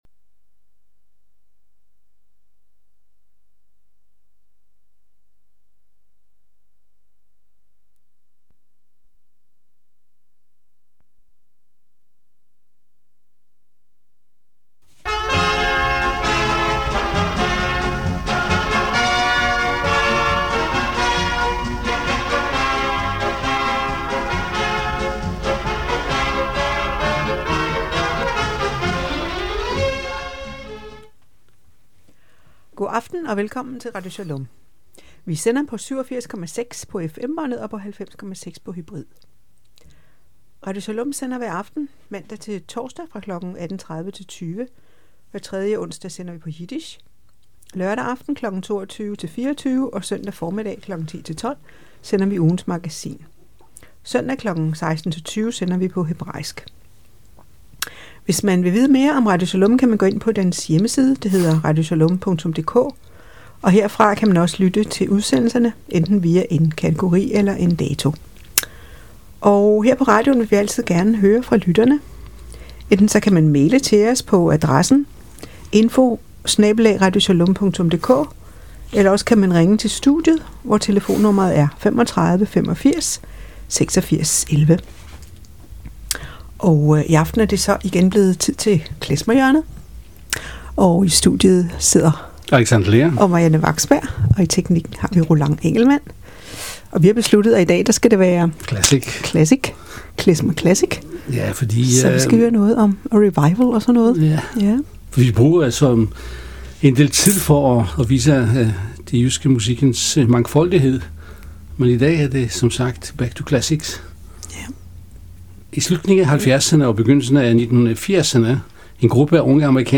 Beskrivelse:Klezmer hjørne